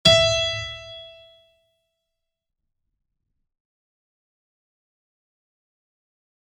piano-sounds-dev
e4.mp3